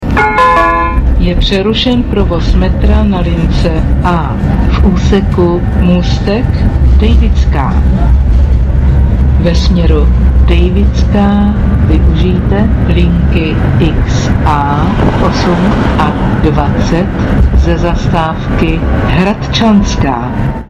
Samostatnou kapitolou je ovšem důkladně promyšlený systém hlášení ve stanicích a vozech metra, v tramvajích a autobusech.
- Hlášení o výluce v tramvajích (info o výluce) si